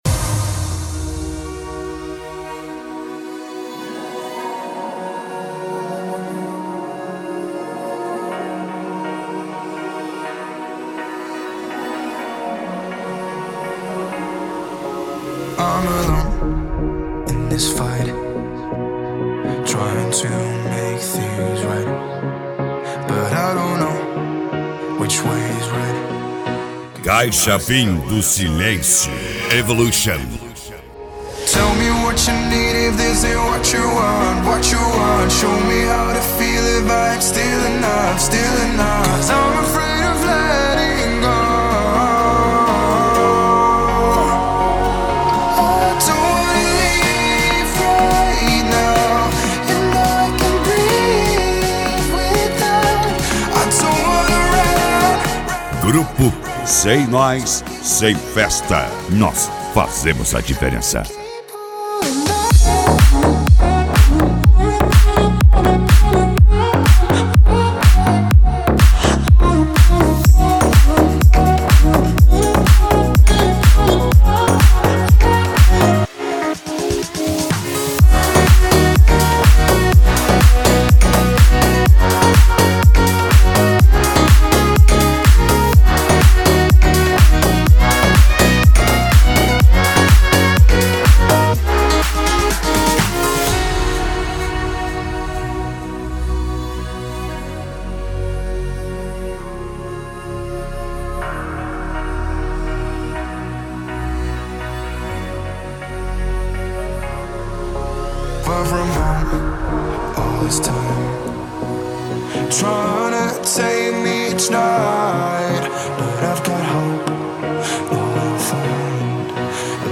Eletronica
Funk
Funk Nejo
Mega Funk